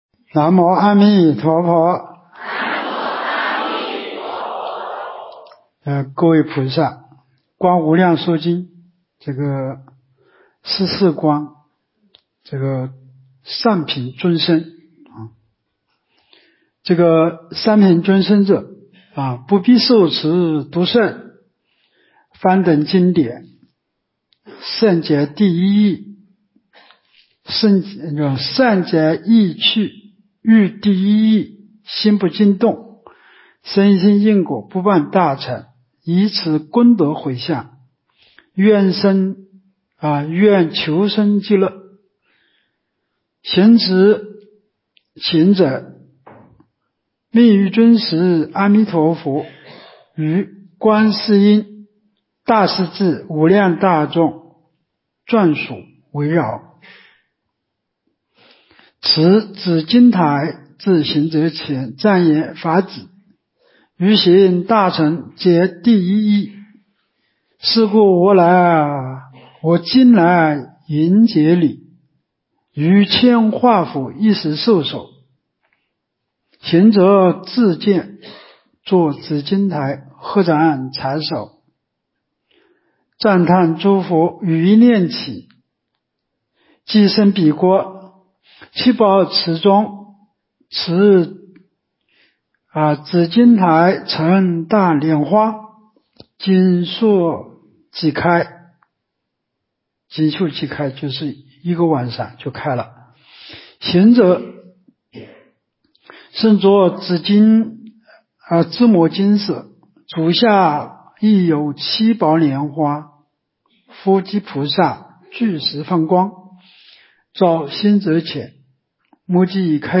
无量寿寺冬季极乐法会精进佛七开示（34）（观无量寿佛经）...